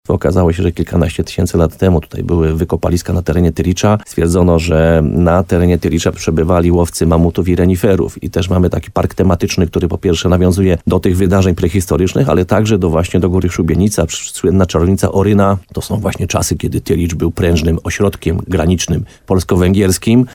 – mówi Piotr Ryba, burmistrz Krynicy-Zdroju.